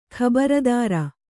♪ khabardāra